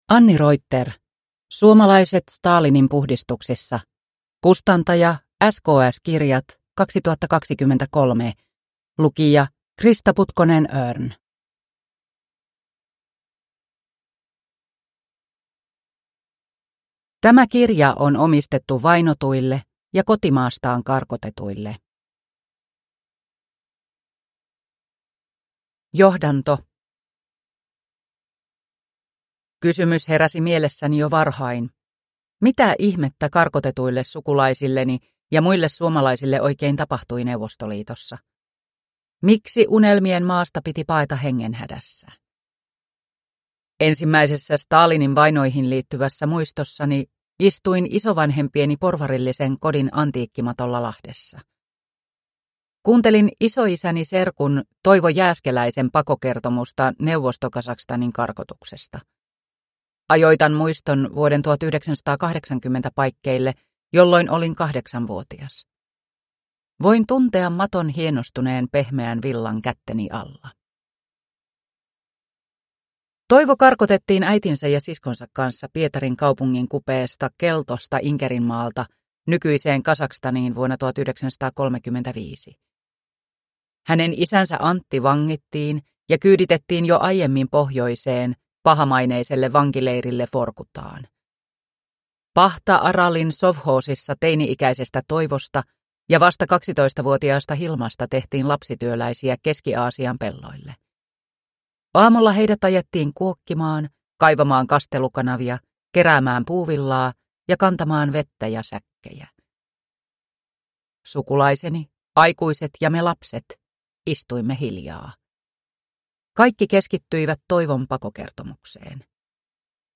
Suomalaiset Stalinin puhdistuksissa – Ljudbok – Laddas ner